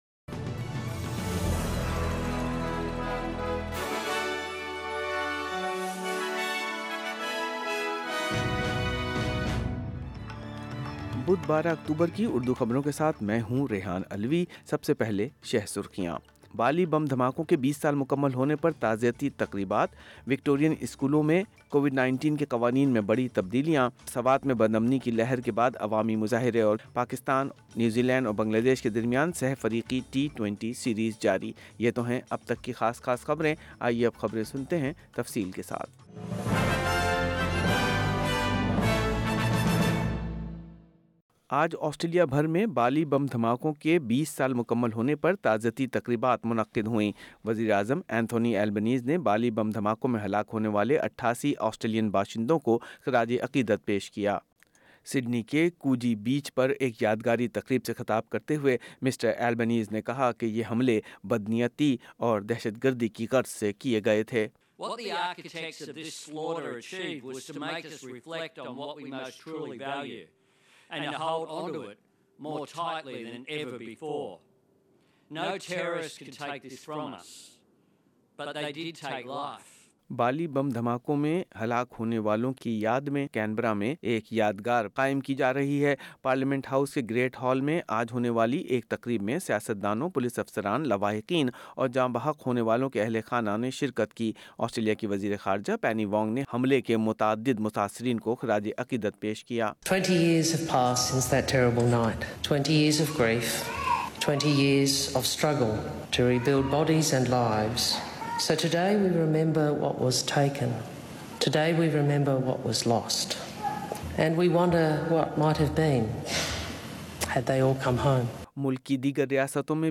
Full News bulletin in Urdu - 12 October 2022